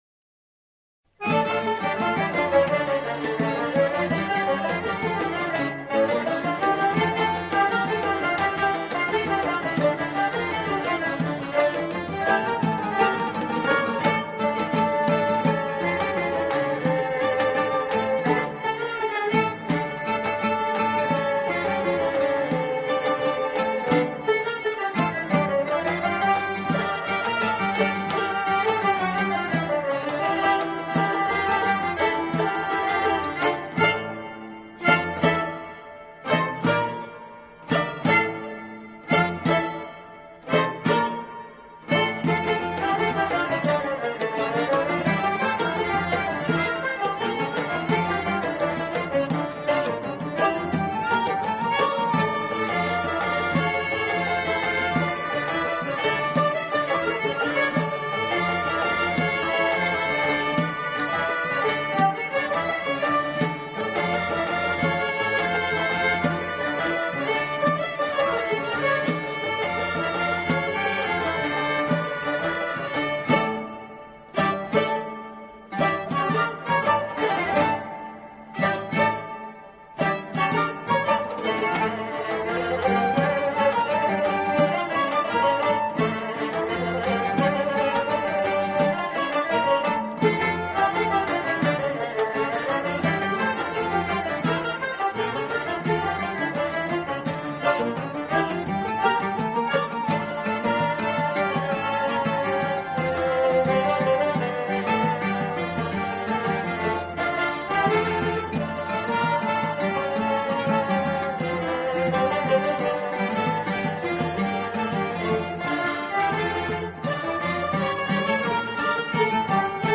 سرودهای انگیزشی
بی‌کلام